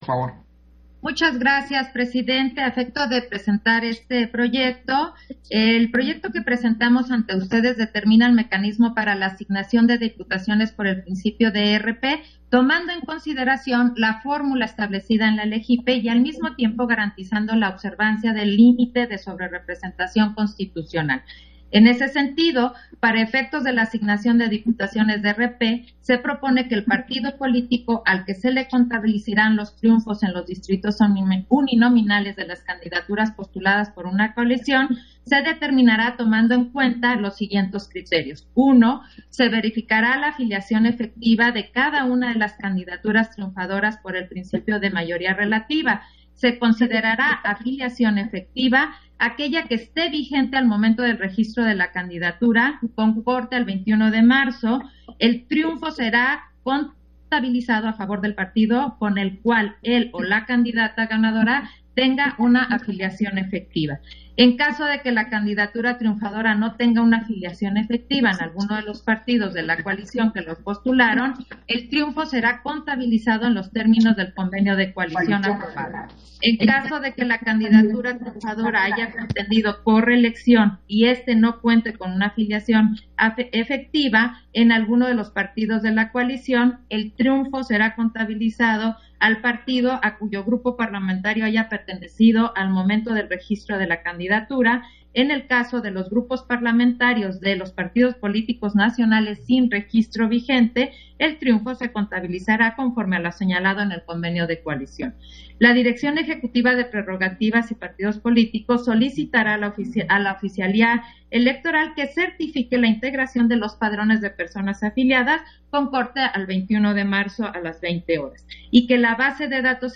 Intervención de Claudia Zavala en el punto de la segunda Sesión Extraordinaria, por el que se determina el mecanismo para la aplicación de la fórmula de asignación de curules por el principio de representación proporcional